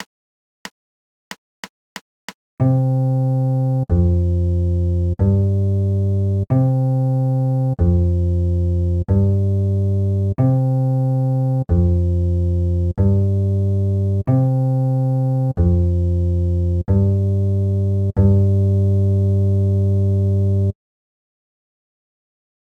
noisy accoustic midi bass
I put here a link to a short .M4a file of an accoustic midi bass (33) with a satured sound done with a macbook and BIAB 2010.
I tried to understand why the sound is so bad.
noisy_bass_sample.m4a